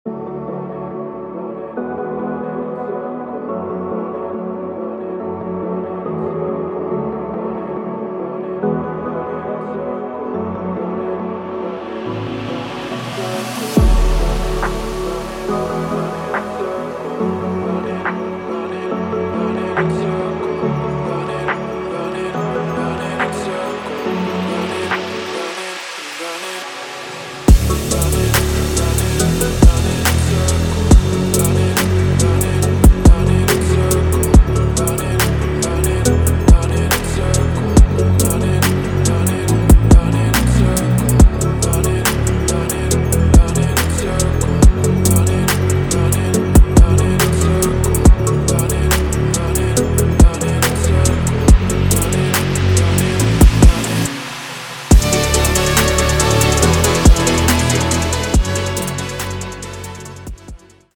Minimal
Deep and Progressive House